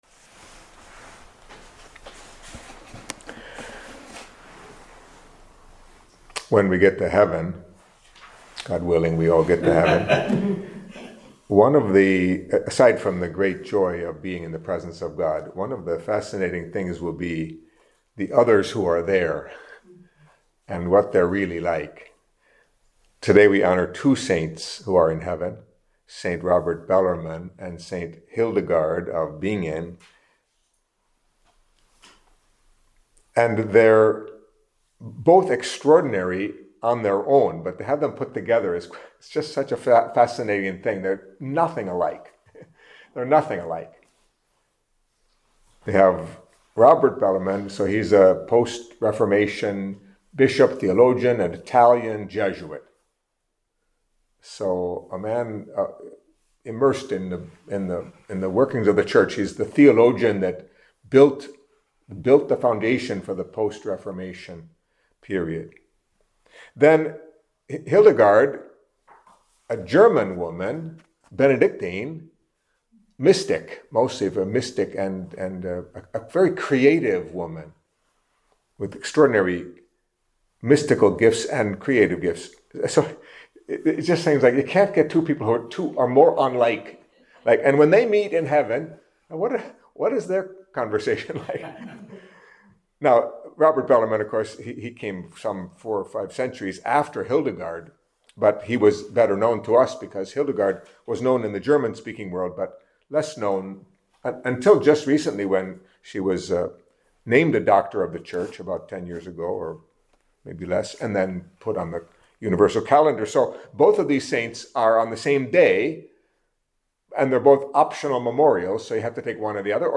Catholic Mass homily for Wednesday of the Twenty-Fourth Week in Ordinary Time